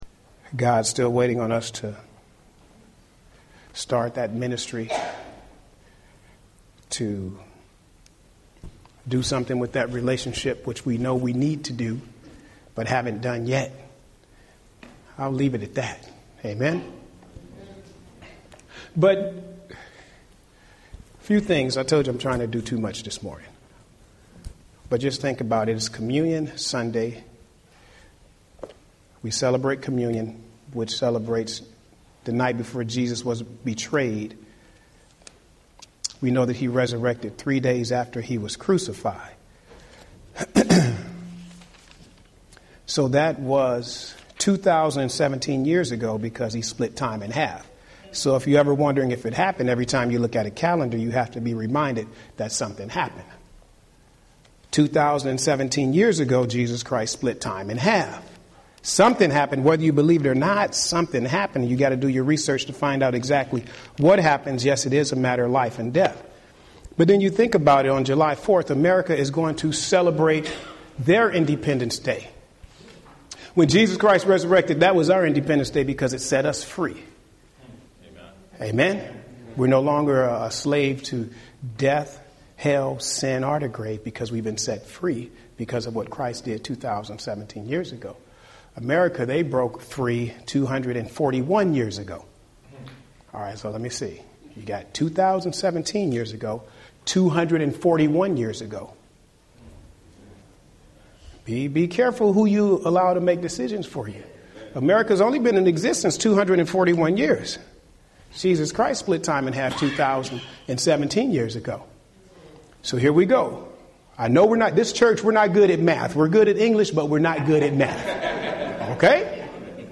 Home › Sermons › Communion Sunday: Blind Beggar or Rich Sinner!